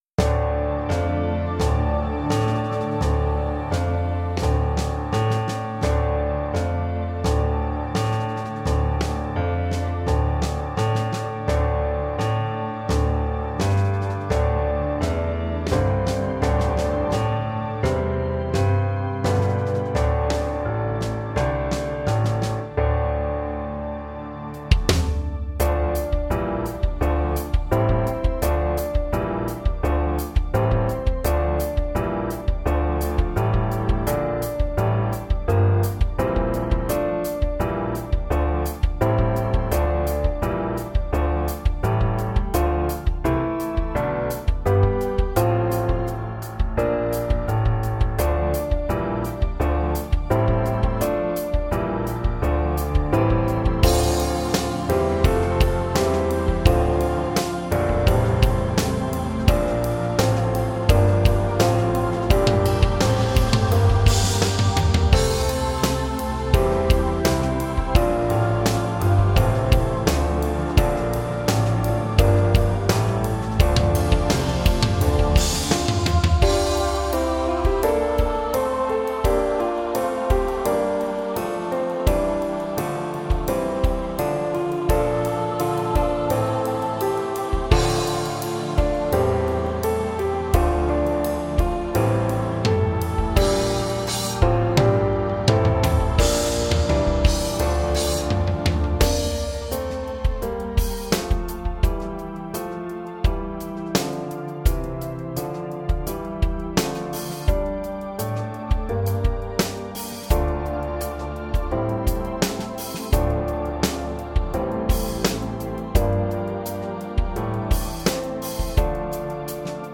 Trombone